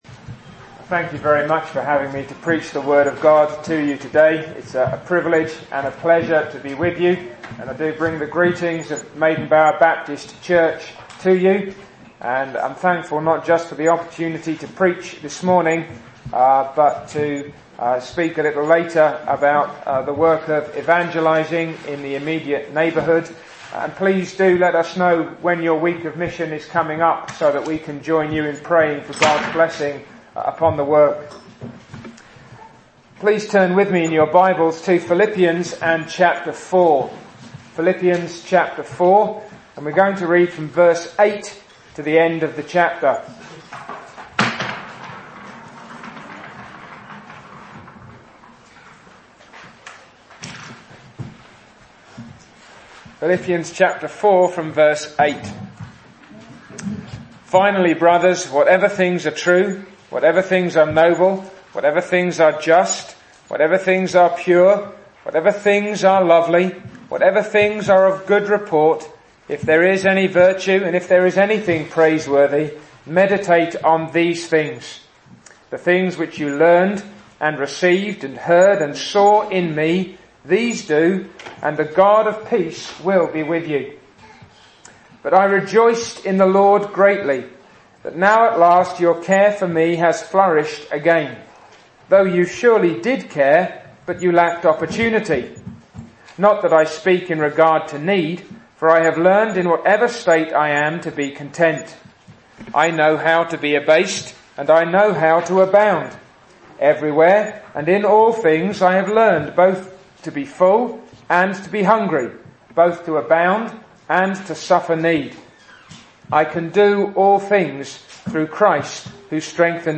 2016 Service Type: Sunday Morning Speaker